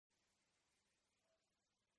Free SFX sound effect: Balloon Pop.
Balloon Pop
277_balloon_pop.mp3